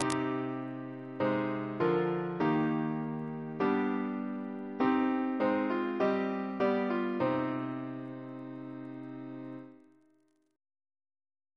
Single chant in C minor Composer: Edward F. Rimbault (1816-1876) Reference psalters: ACB: 326; OCB: 339; PP/SNCB: 143